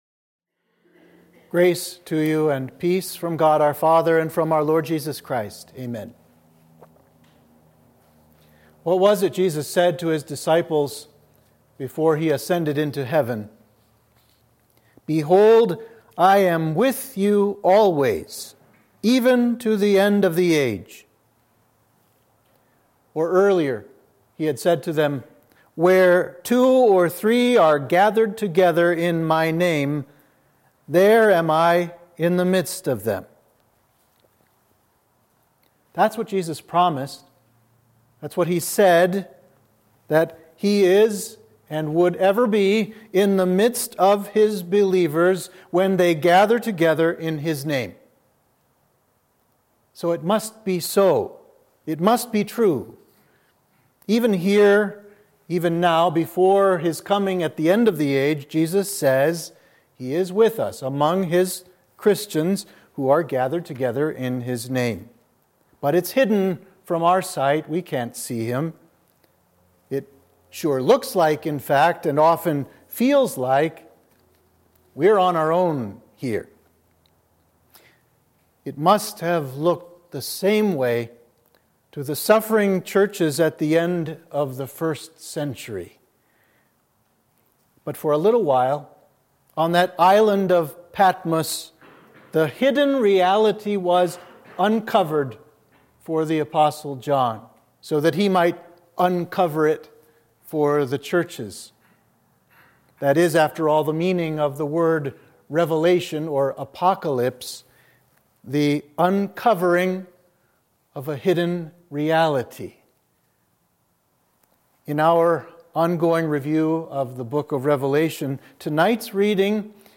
Sermon for Midweek of Advent 3